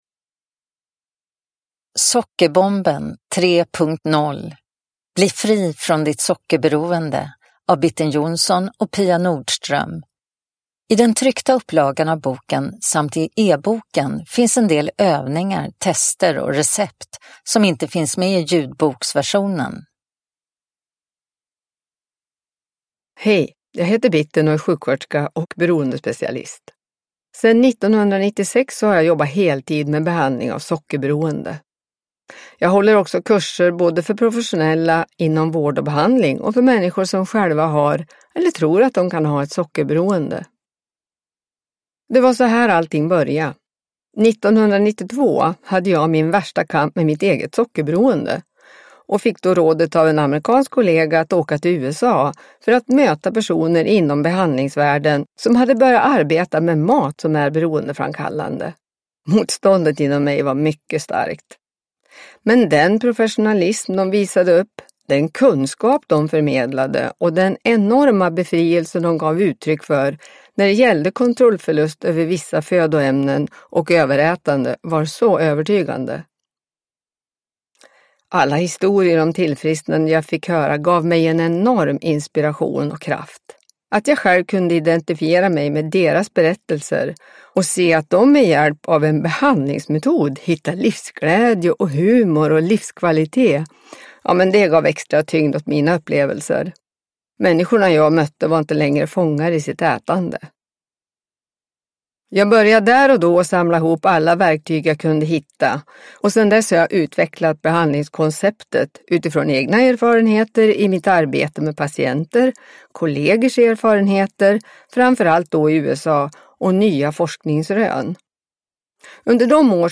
Sockerbomben 3.0 : bli fri från ditt sockerberoende – Ljudbok – Laddas ner